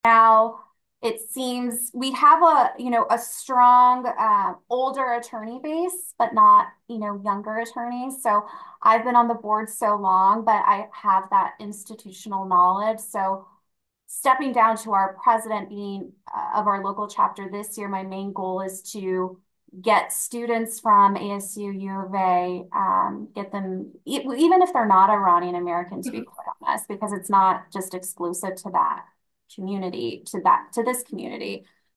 Interview Clips